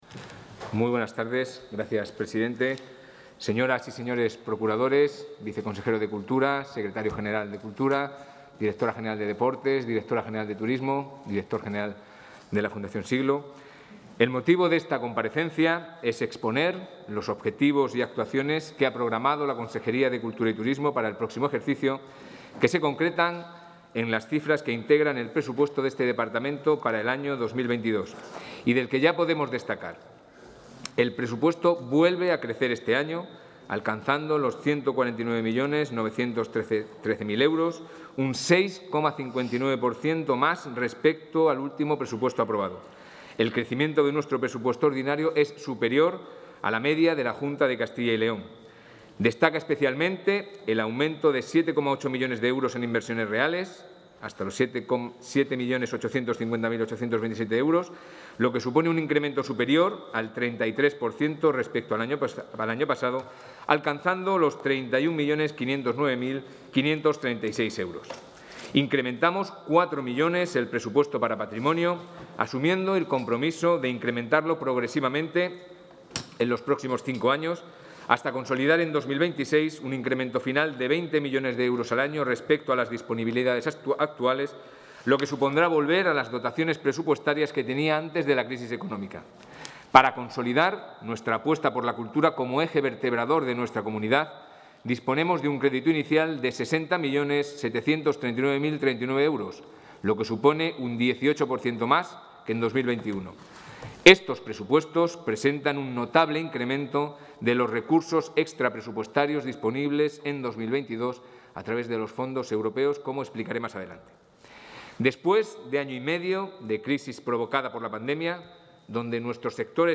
El consejero de Cultura y Turismo, Javier Ortega, ha comparecido esta tarde ante la Comisión de Economía y Hacienda de las Cortes de...
Comparecencia del consejero de Cultura y Turismo.